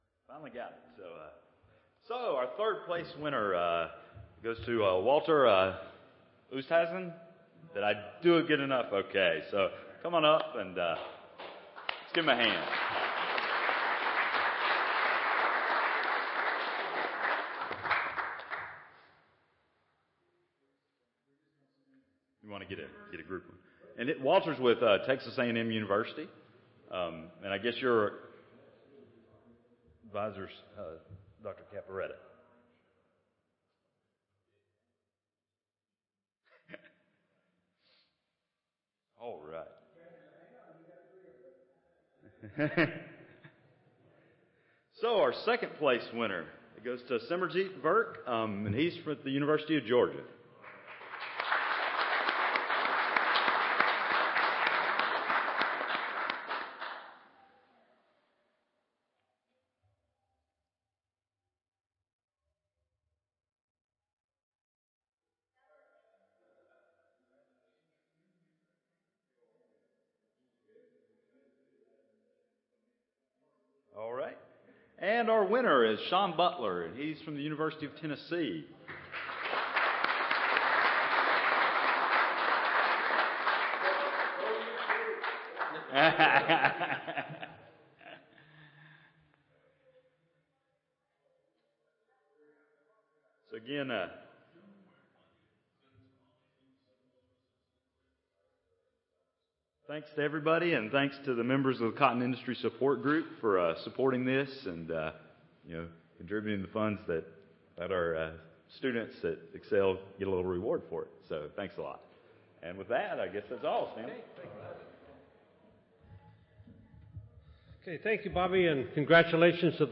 Cotton Ginning - Friday Early Morning
Audio File Recorded Presentation